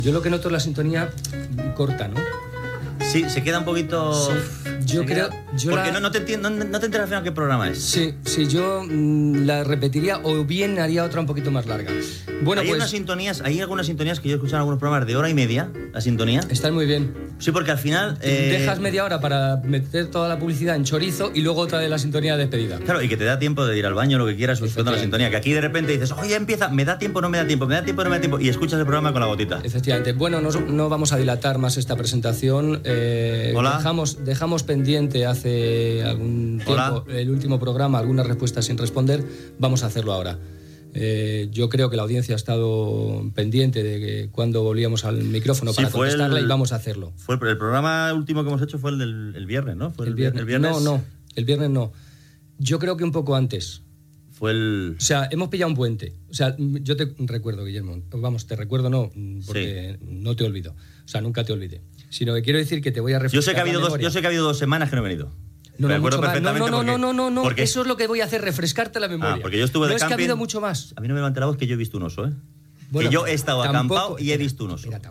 Diàleg entre els dos presentadors sobre la durada de la sintonia.
Entreteniment